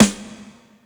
Tuned snare samples Free sound effects and audio clips
• Big Snare Drum Sound G# Key 416.wav
Royality free snare drum sound tuned to the G# note. Loudest frequency: 1961Hz
big-snare-drum-sound-g-sharp-key-416-8Kr.wav